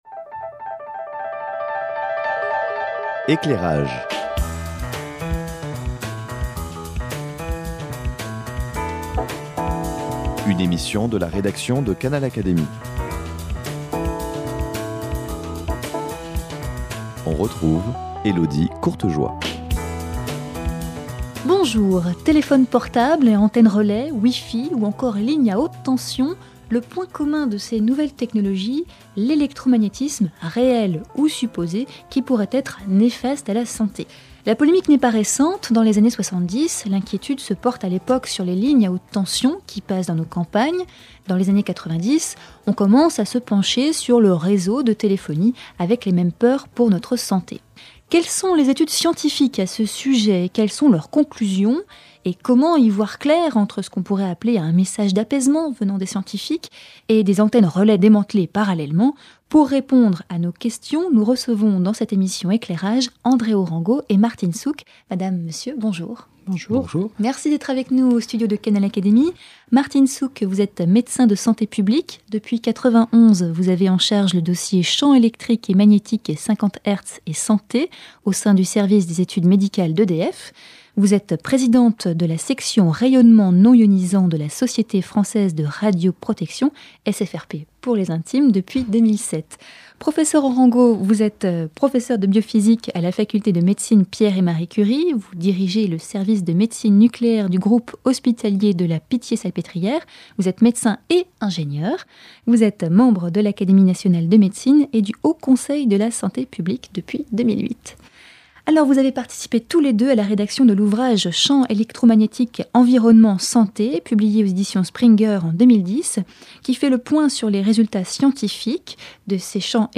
deux médecins spécialisés sur ces questions, vous apportent des réponses, conclusions scientifiques à l’appui.